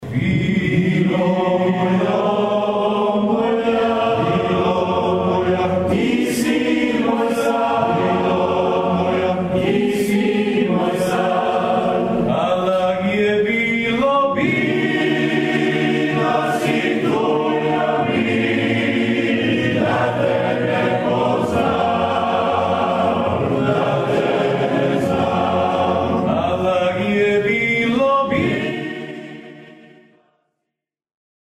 Klapska večer u Dvorcu
Klapska večer priređena je u subotu u Dvorcu grofa Jankovića.Klapske izvedbe rado slušanih pjesama u akustički, moćnom prostoru u kojem posebno dolazi do izražaja punoća zvuka i savršeno usklađeno višeglasje, program su koji može probuditi emocije i ispuniti očekivanja publike.Zajednička izvedba pjesme Vilo moja u čast Vinku Coci u interpretaciji svih izvođača sedam kontinentalnih klapa u subotu je glazbom povezala i publiku i izvođače.